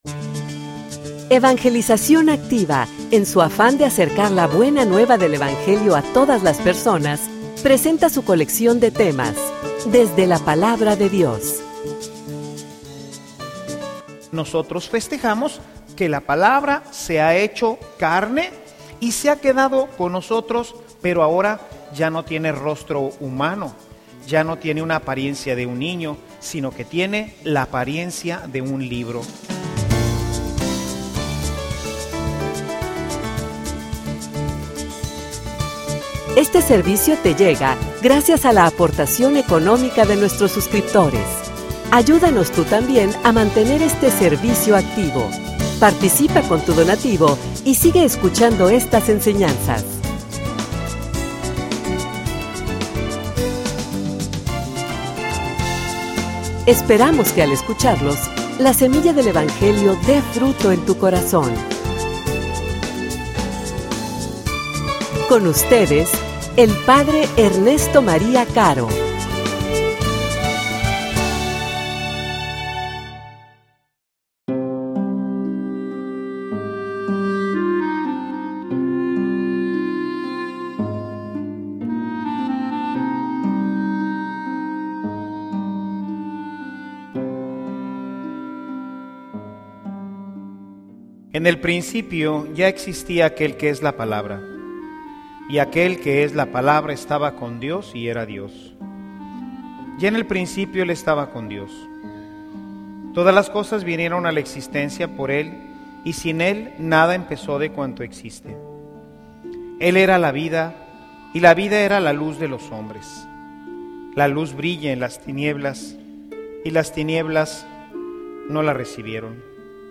homilia_La_Palabra_habita_entre_nosotros.mp3